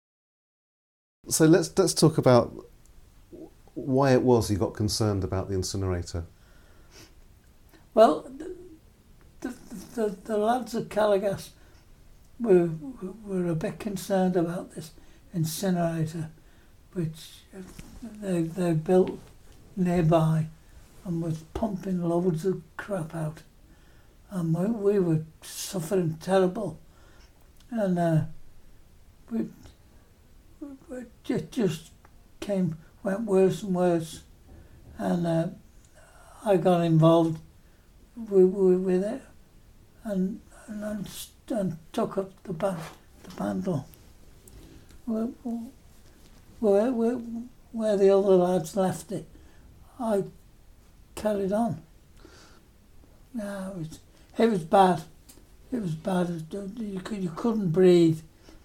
A clip from an interview